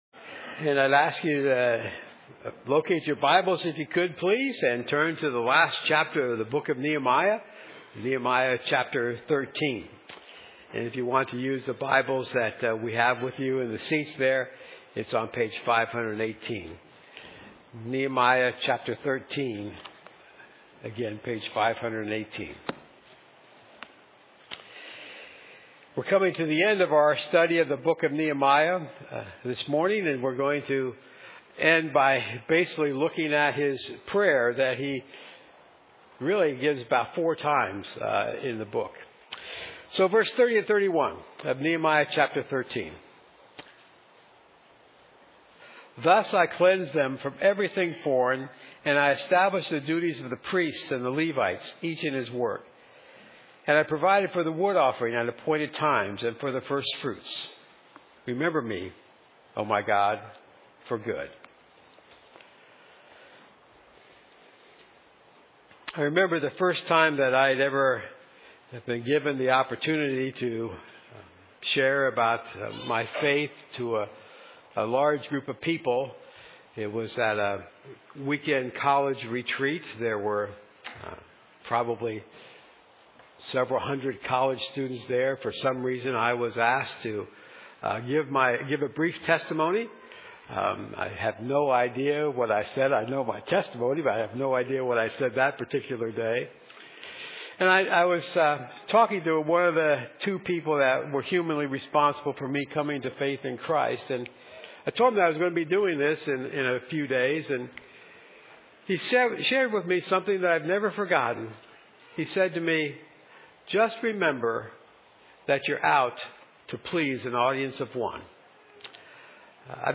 Nehemiah 13:30-31 Service Type: Morning God’s people should focus their actions and prayers on the favor of God.